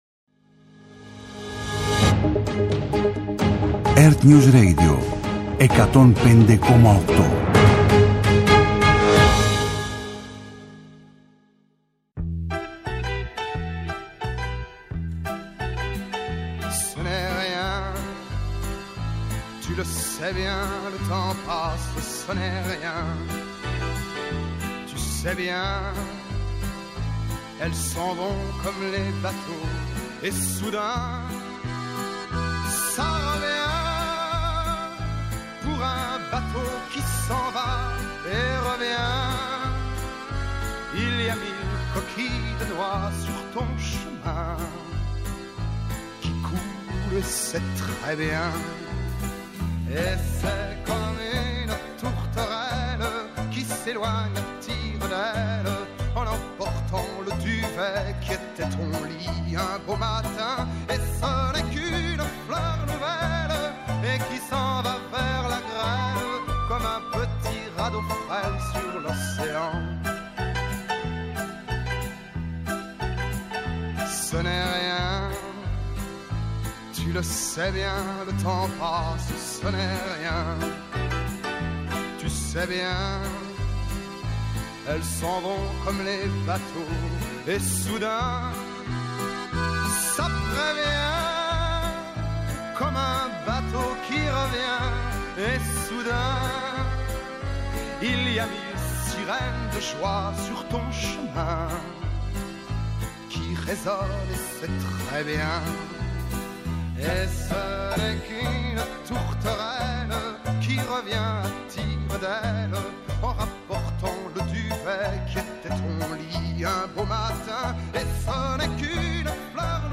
Ενημέρωση με έγκυρες πληροφορίες για όλα τα θέματα που απασχολούν τους πολίτες. Συζήτηση με τους πρωταγωνιστές των γεγονότων. Ανάλυση των εξελίξεων στην Ελλάδα και σε όλο τον πλανήτη και αποκωδικοποίηση της σημασίας τους. ΕΡΤNEWS RADIO